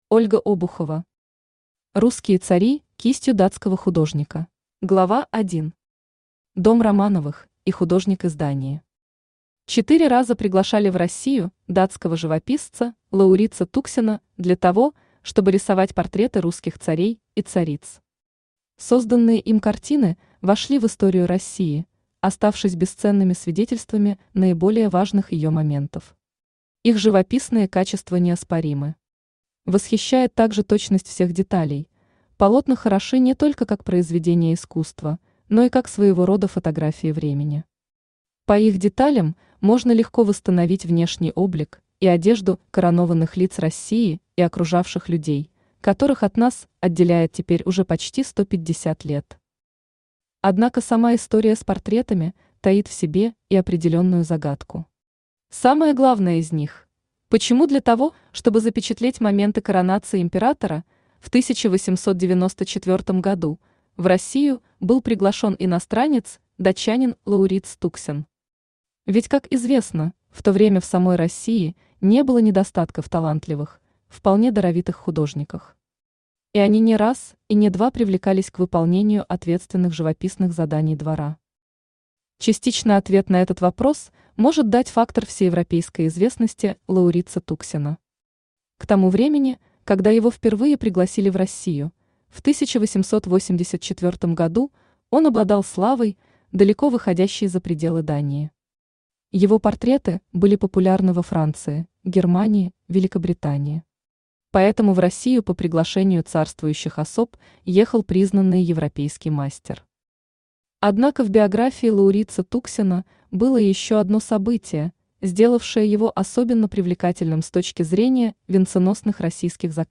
Аудиокнига Русские цари кистью датского художника | Библиотека аудиокниг
Aудиокнига Русские цари кистью датского художника Автор Ольга Ивановна Обухова Читает аудиокнигу Авточтец ЛитРес.